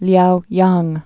(lyouyäng)